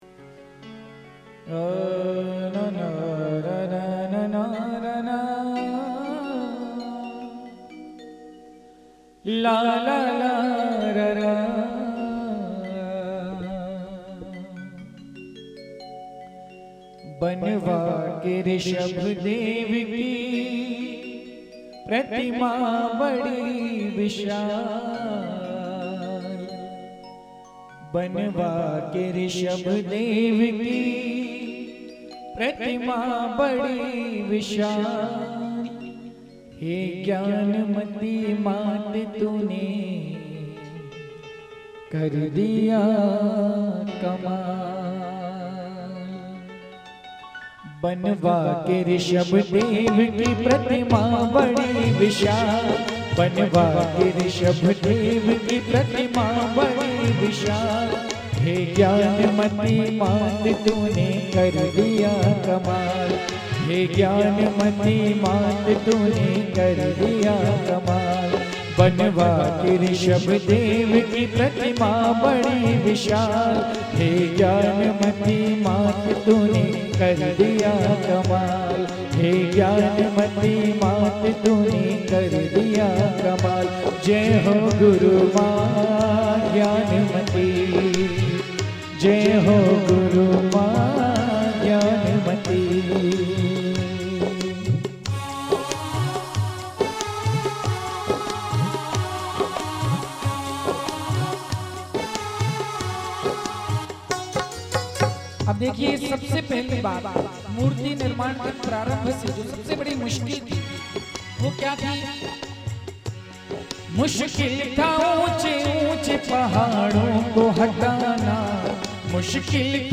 Agar Asma Tak Banwa Ke Rishabhdev Ki Pratima Badi Vishal Bhajan